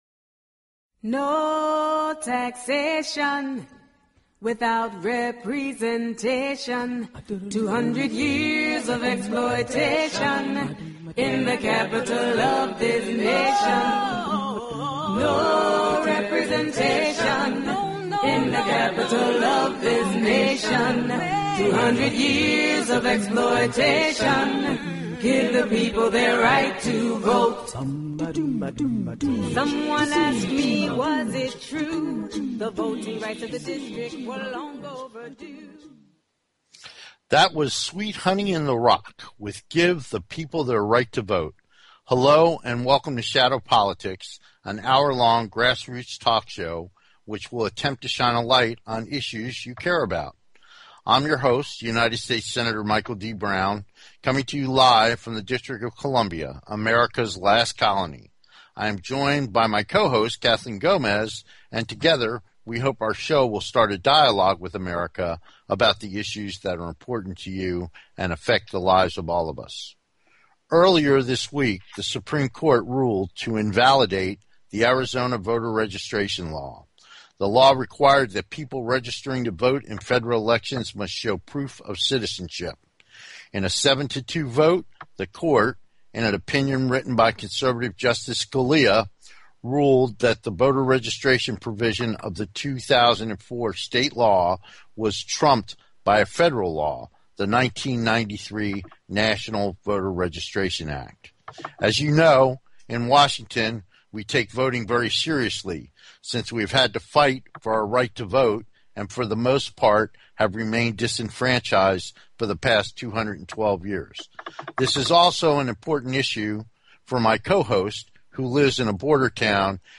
Shadow Politics is a grass roots talk show giving a voice to the voiceless.
We look forward to having you be part of the discussion so call in and join the conversation.